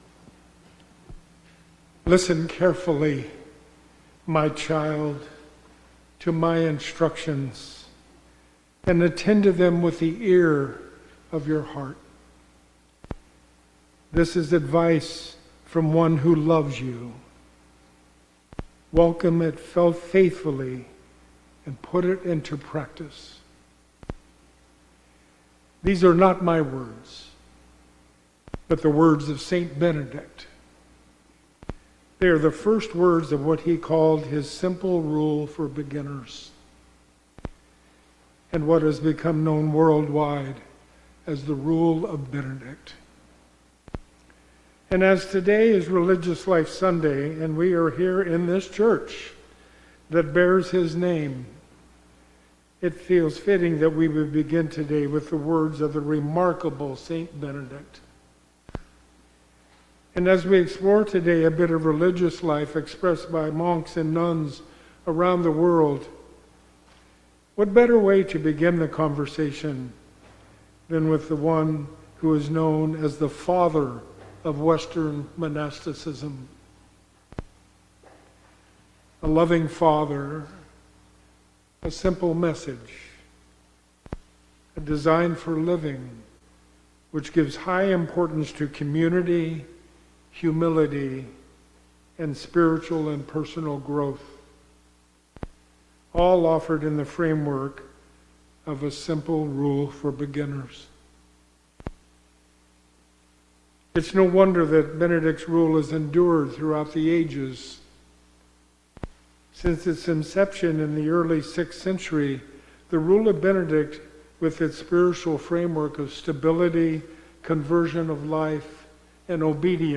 Epiphany 3 – 1/25/26 – Sermon
1-25-26-Sermon.mp3